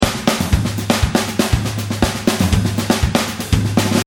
| samba style drum loop |